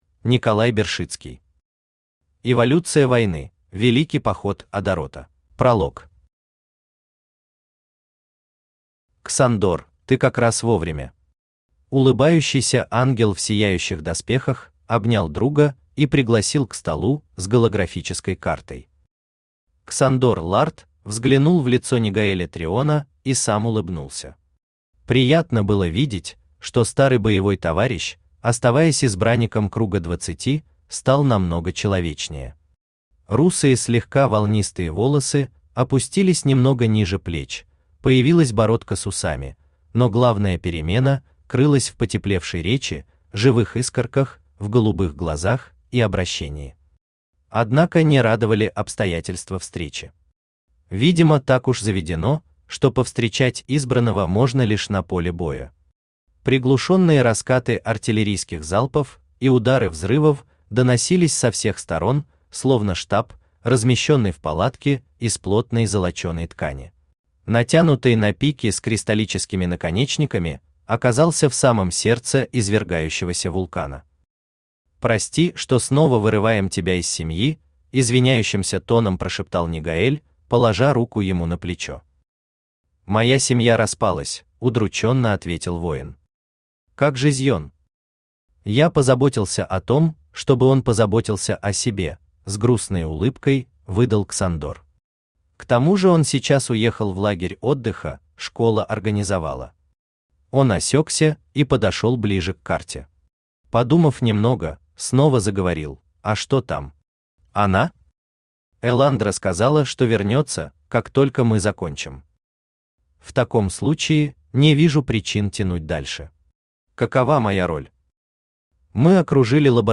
Аудиокнига Эволюция войны: Великий поход Адарота | Библиотека аудиокниг
Aудиокнига Эволюция войны: Великий поход Адарота Автор Николай Олегович Бершицкий Читает аудиокнигу Авточтец ЛитРес.